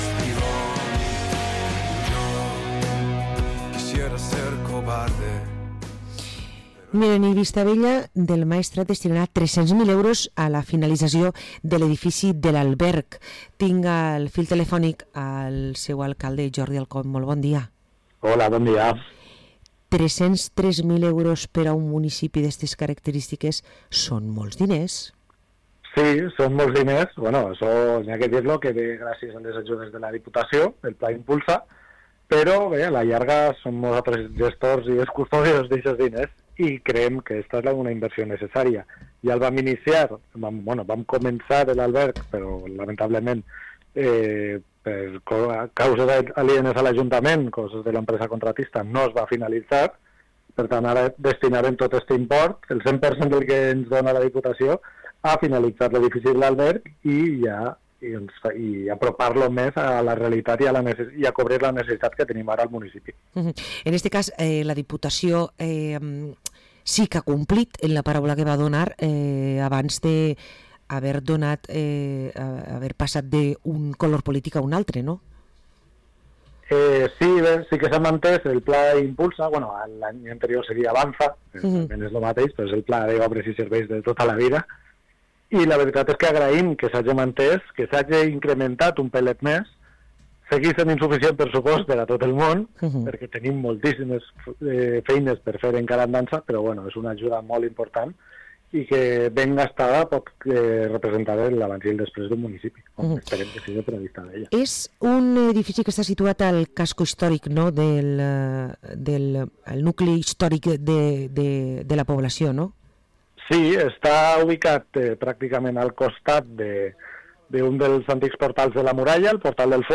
Parlem amb Jordi Alcon, Alcalde de Vistabella del Maestrat